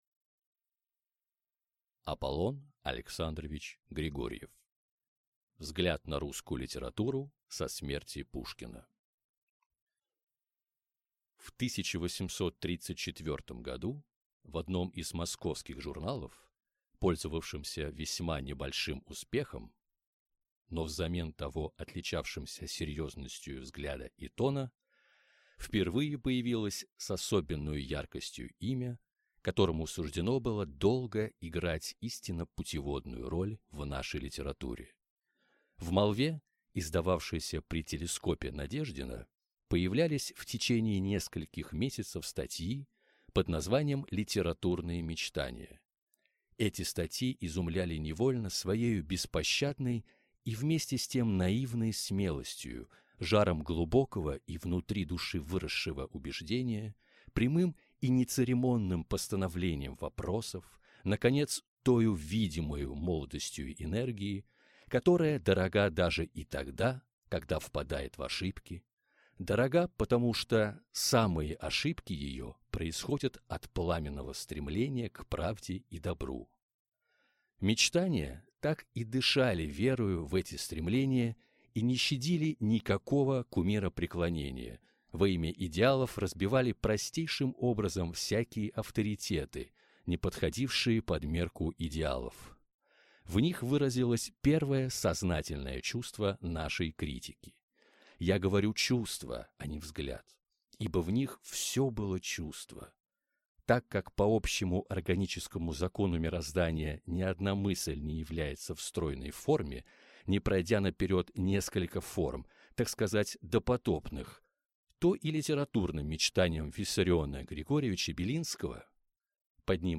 Аудиокнига Взгляд на русскую литературу со смерти Пушкина | Библиотека аудиокниг
Прослушать и бесплатно скачать фрагмент аудиокниги